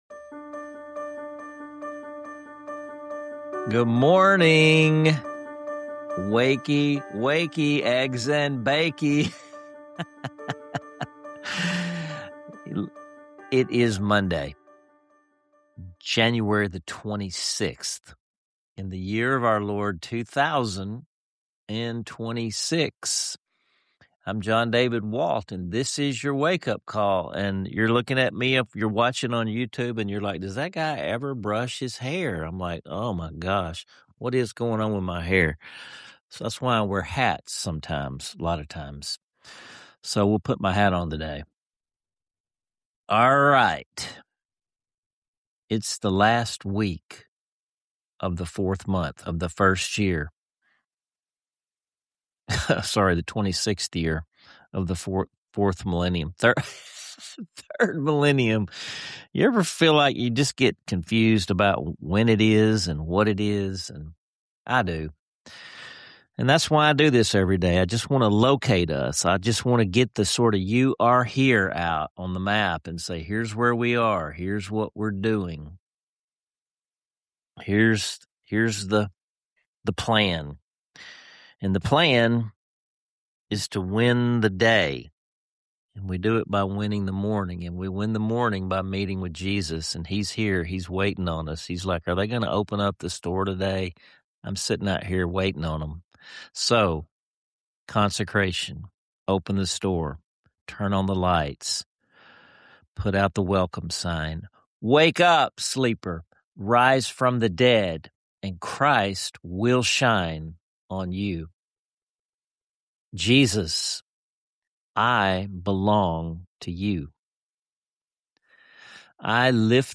Key moments include a provocative comparison between artificial intelligence (AI) and “Jesus intelligence” (JI), challenging us to prioritize spiritual wisdom over quick solutions. You'll also hear an uplifting prayer and worship segment that grounds the show’s themes in authentic faith, along with practical journal prompts to help you reflect and grow.